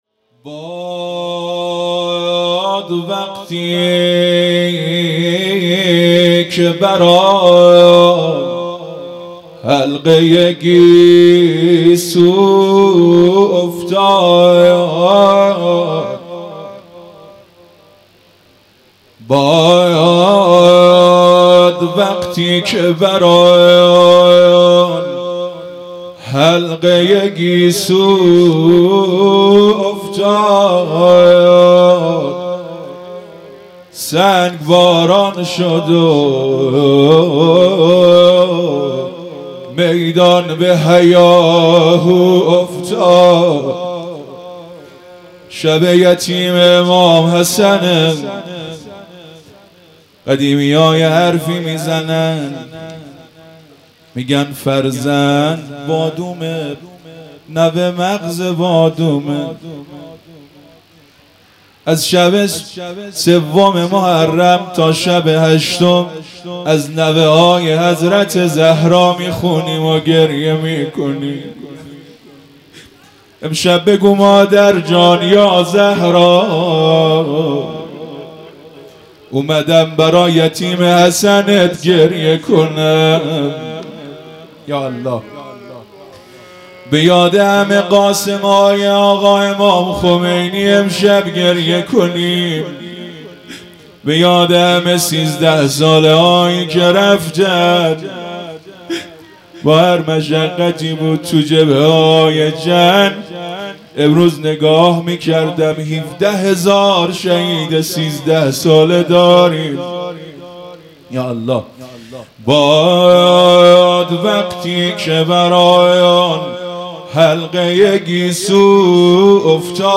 مراسم عزاداری محرم الحرام ۱۴۴۳_شب ششم